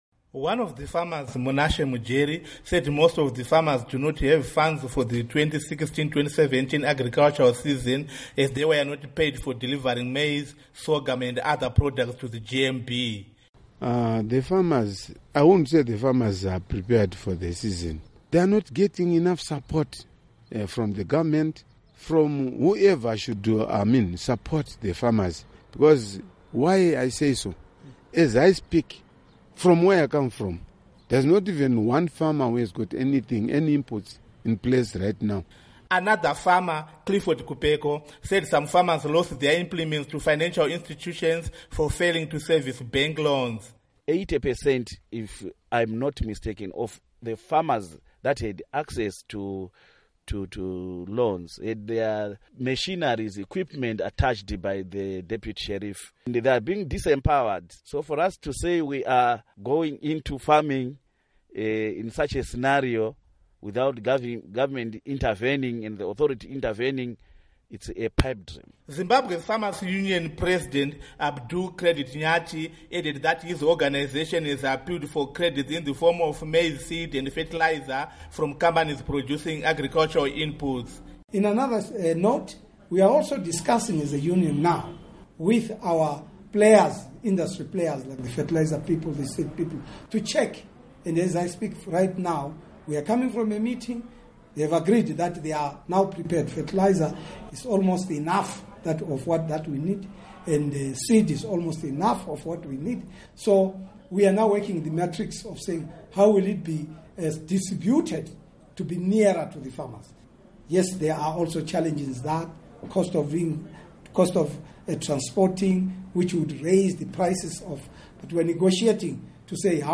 Report on Zimbabwe Farmers